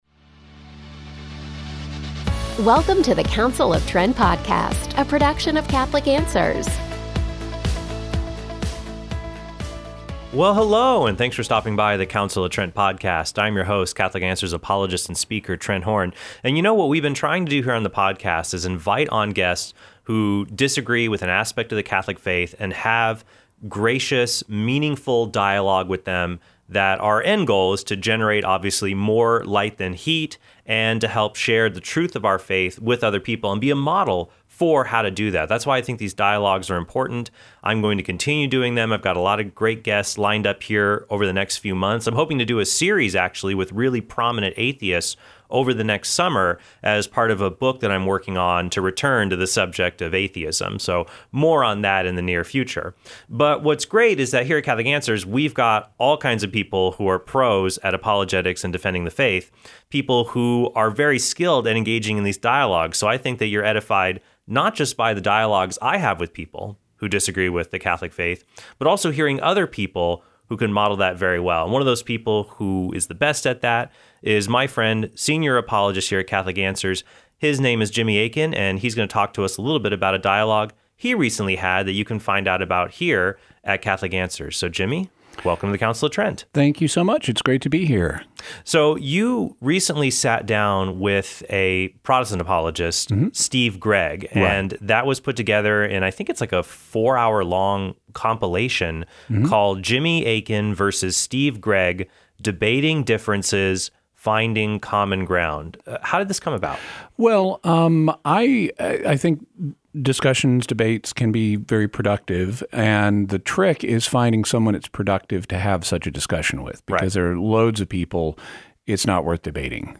dialogue